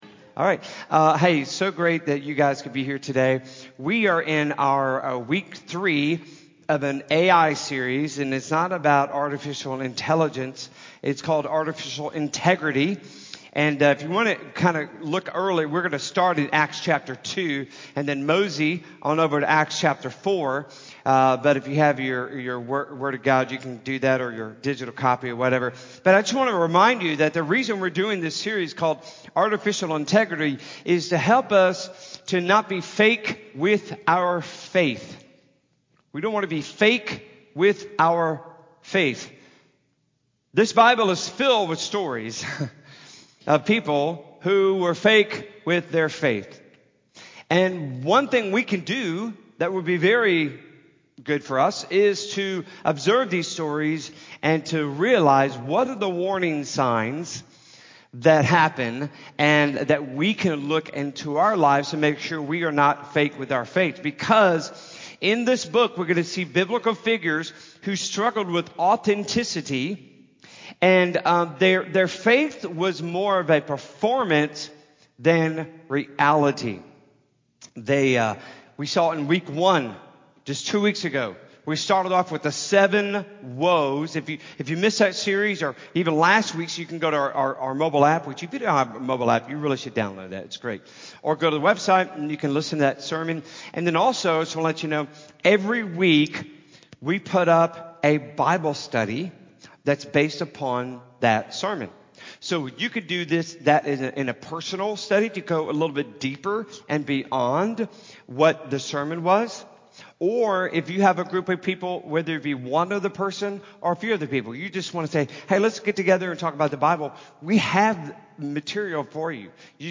What-Are-You-Holding-Back-Sermon-Audio-CD.mp3